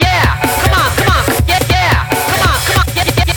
drums07.wav